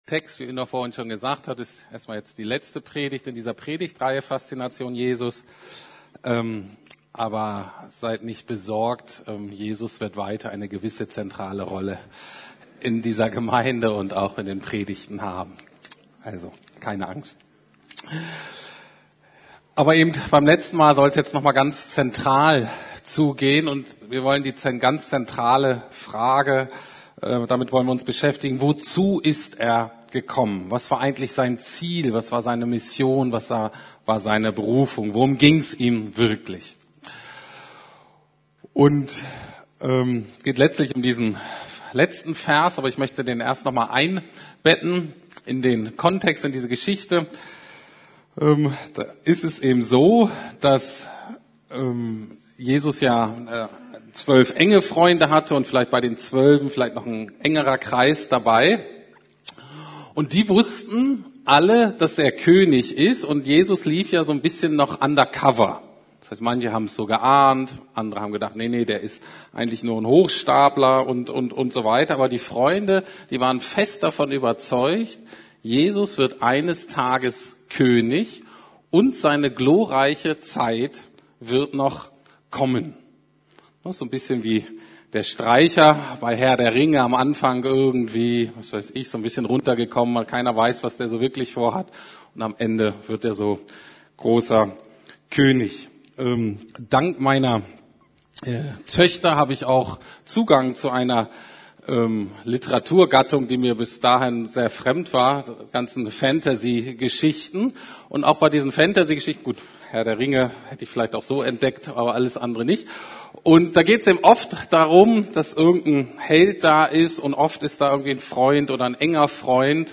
Faszination Jesus: Jesus unser Retter ~ Predigten der LUKAS GEMEINDE Podcast